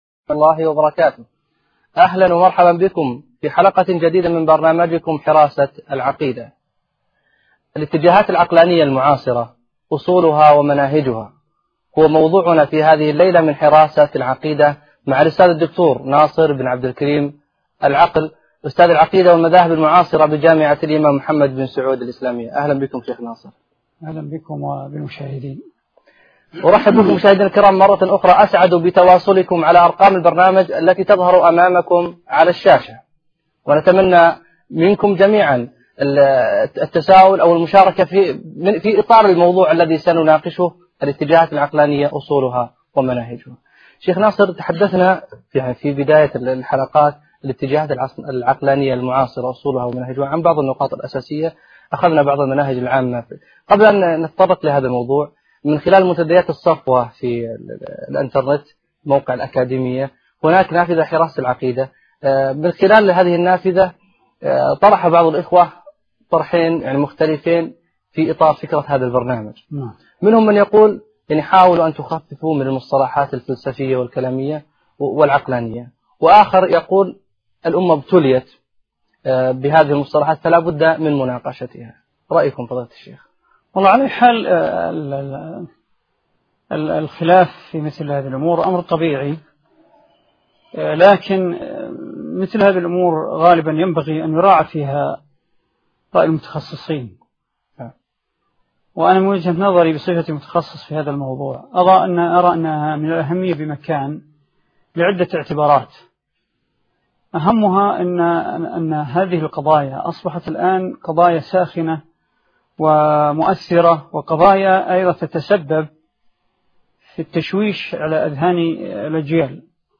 لدروس وخطب ومحاضرات